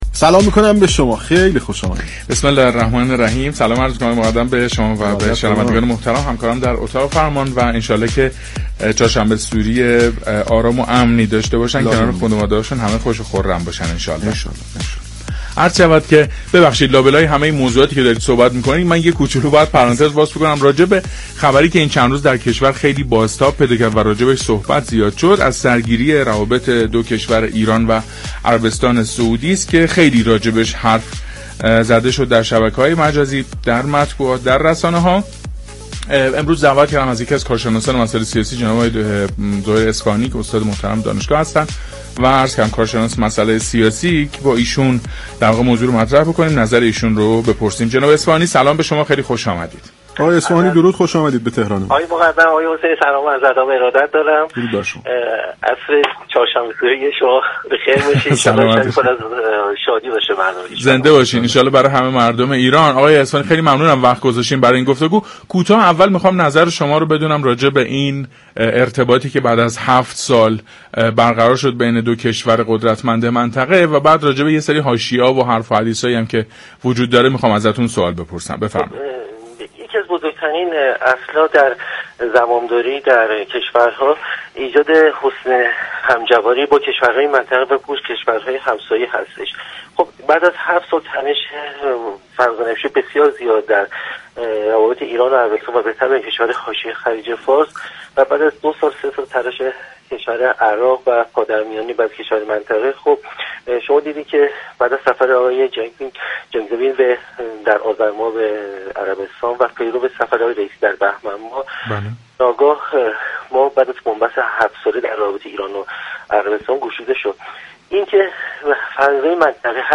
كارشناس سیاسی در گفت و گو با «تهران من»